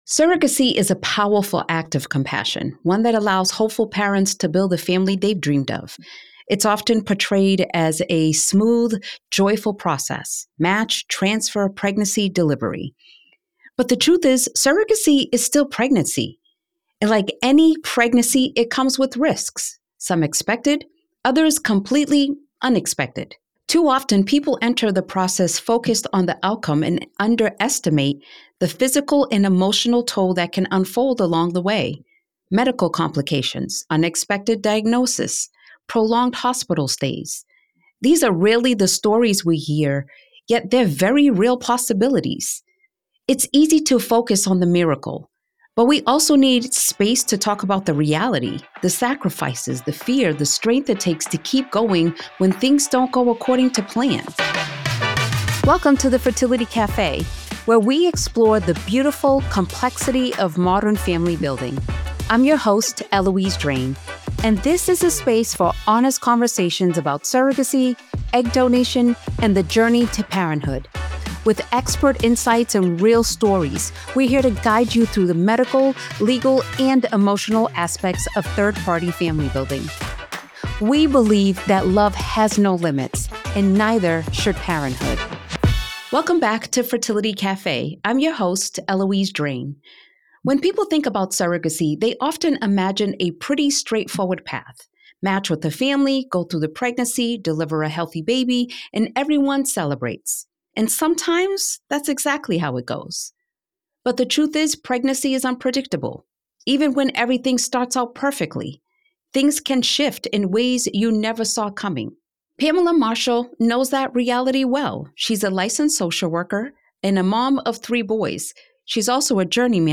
What Surrogacy Really Feels Like: A Candid Conversation